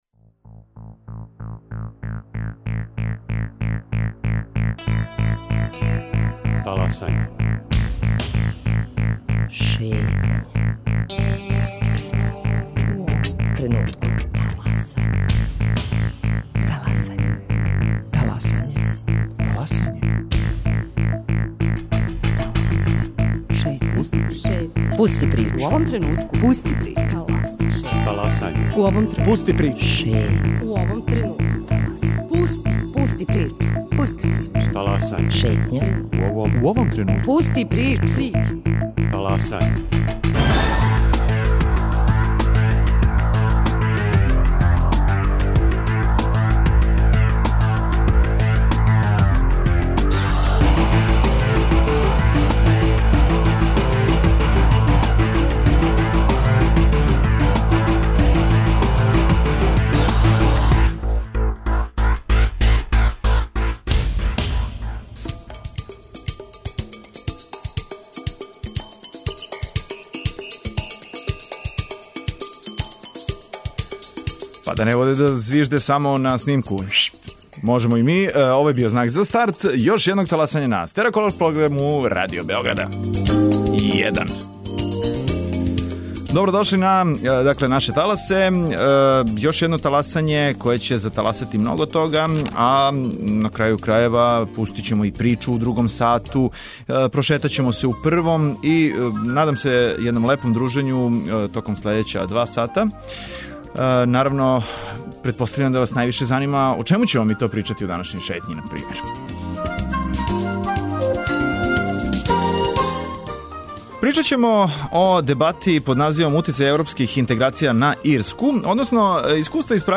Гошћа Шетње биће Ен Пешић, конзул Републике Ирске у Србији, са којом ћемо разговарати о трибини уприличеној у Дому омладине, на тему - које грешке не направити у приступању ЕУ.